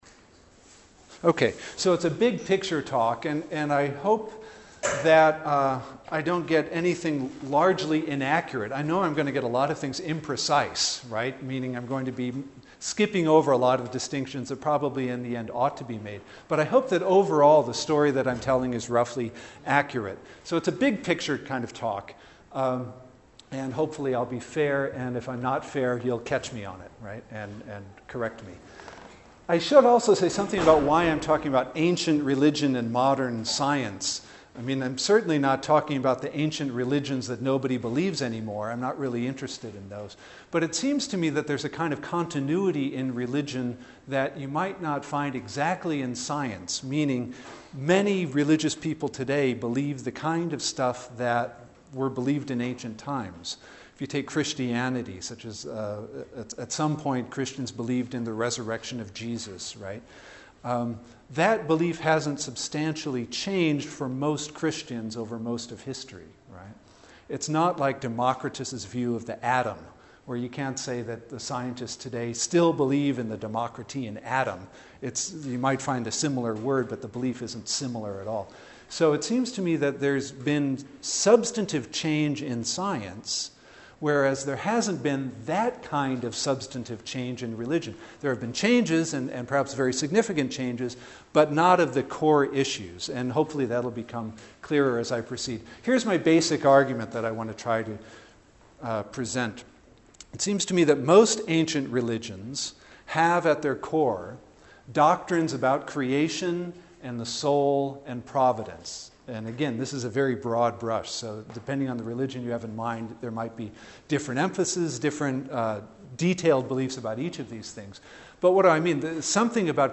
In anticipation of my lecture Tuesday, I thought I’d offer a preview.